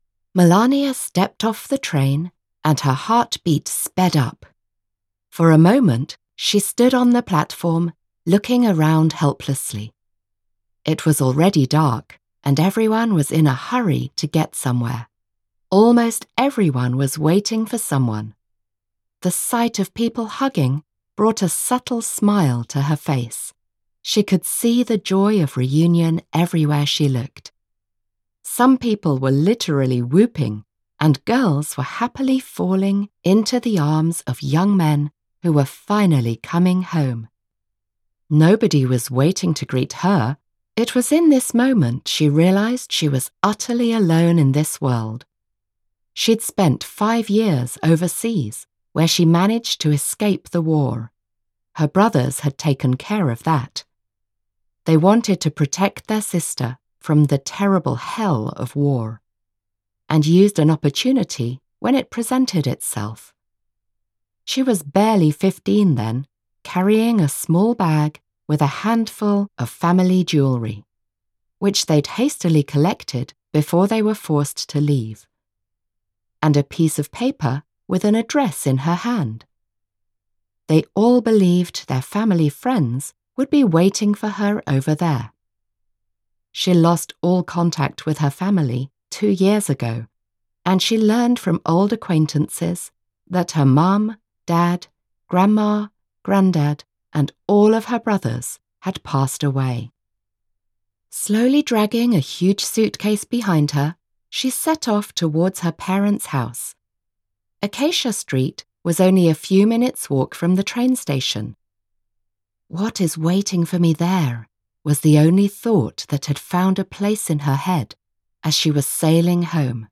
Forgotten shoes audiokniha
Ukázka z knihy